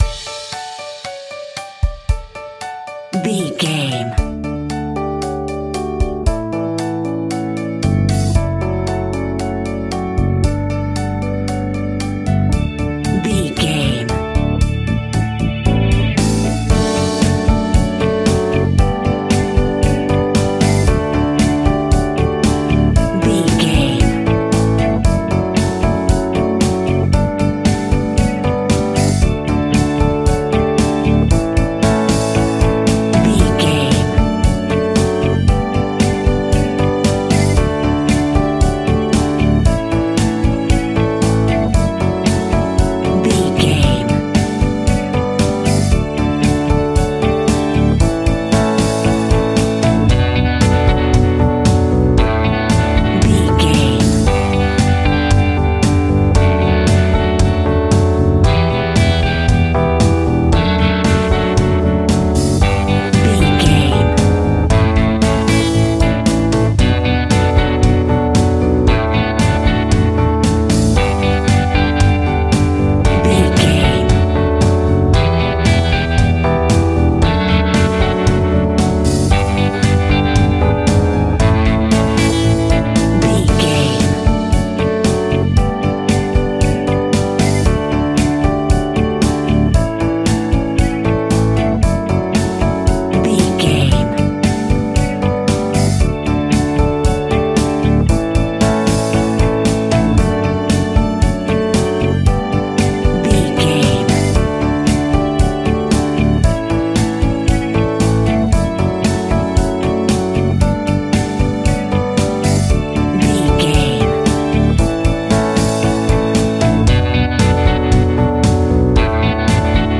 Ionian/Major
D
cheesy
happy
upbeat
bright
bouncy
drums
bass guitar
electric guitar
keyboards
percussion